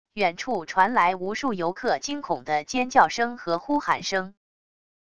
远处传来无数游客惊恐的尖叫声和呼喊声wav音频